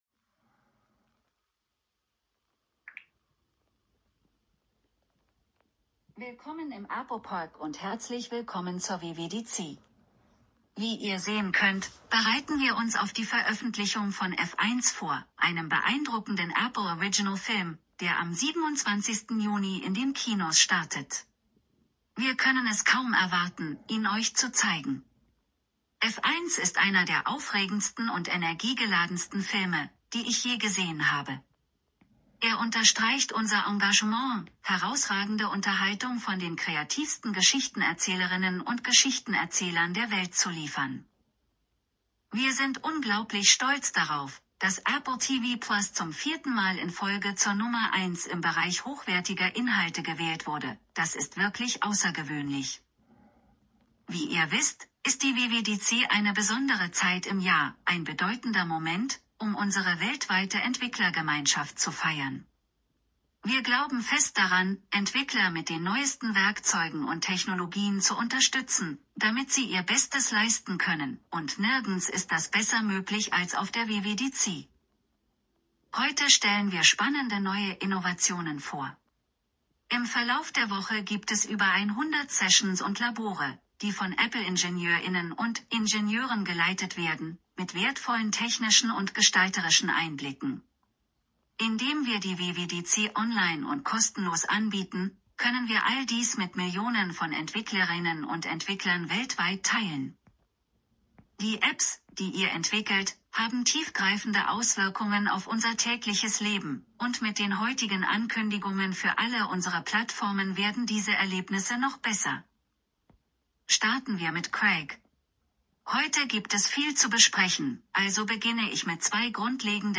Ich habe das gesamte Event vollständig transkribiert, ins Deutsche übersetzt und anschließend mithilfe der Vorlesefunktion von Microsoft Word vertont.
Die Qualität der eingesetzten Stimme entspricht jedoch nicht meinen üblichen Standards.
Auch wenn die Tonqualität nicht ideal ist, ist die deutsche Übersetzung vollständig und inhaltlich korrekt – die Informationen sind verständlich aufbereitet. Es handelt sich um eine eins zu eins Übersetzung der Apple Keynote 2025.